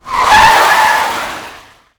brute_highfriction.wav